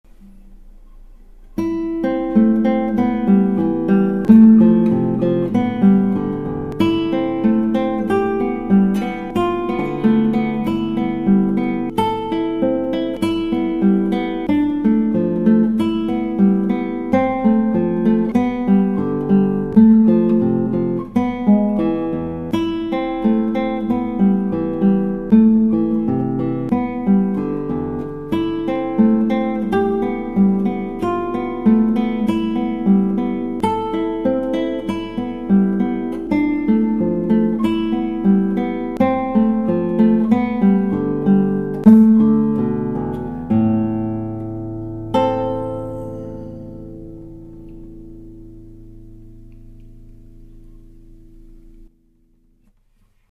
以上ギターはアルカンヘル